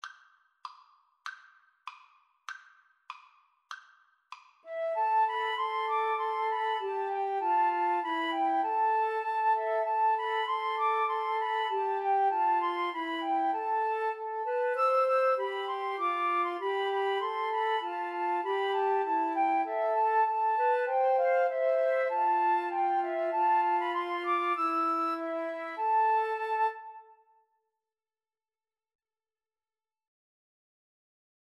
Christmas Christmas Flute Trio Sheet Music Huron Carol
Free Sheet music for Flute Trio
A minor (Sounding Pitch) (View more A minor Music for Flute Trio )
2/4 (View more 2/4 Music)
Flute Trio  (View more Easy Flute Trio Music)
Traditional (View more Traditional Flute Trio Music)